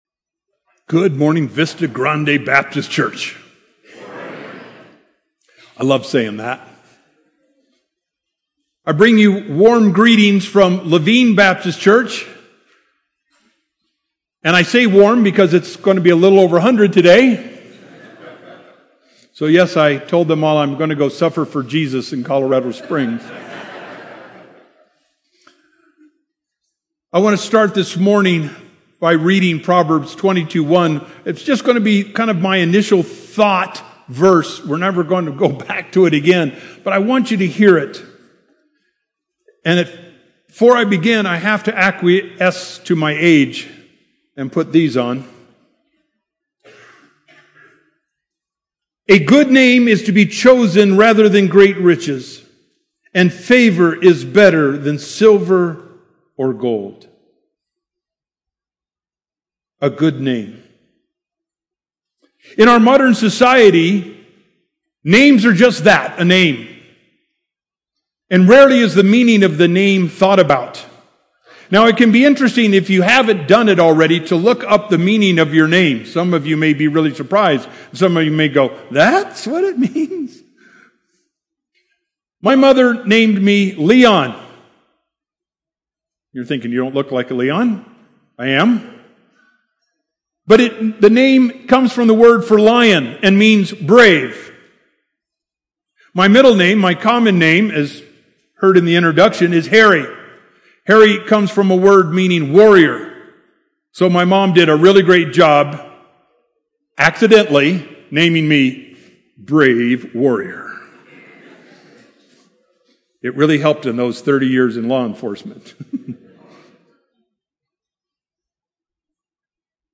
Sermons - Vista Grande Baptist Church